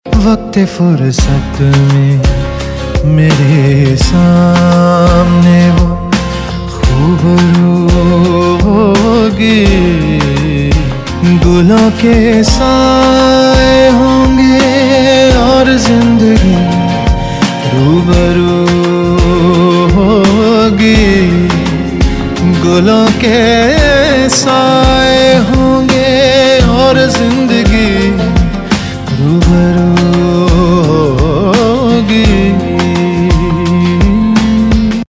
Male Version Ringtone.mp3 Song Download Bollywood Mazafree